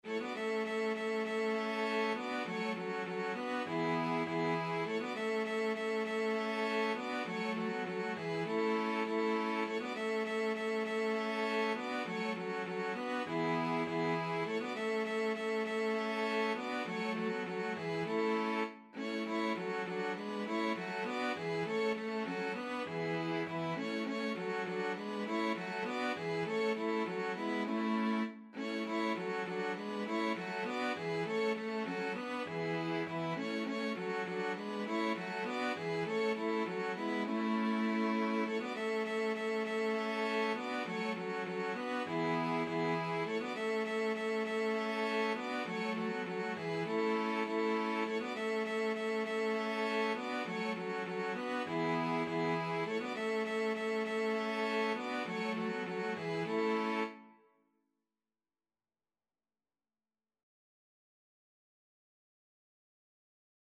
Free Sheet music for String Quartet
A major (Sounding Pitch) (View more A major Music for String Quartet )
4/4 (View more 4/4 Music)
Classical (View more Classical String Quartet Music)
danserye_5_morisque_STRQ.mp3